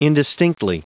Prononciation du mot indistinctly en anglais (fichier audio)
Prononciation du mot : indistinctly